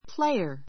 player 小 A1 pléiə r プ れ イア 名詞 ❶ (運動)選手 a baseball player a baseball player 野球選手 a good tennis player a good tennis player テニスのうまい人 ❷ 演奏者 , プレーヤー ; 俳優 That guitar player is very good.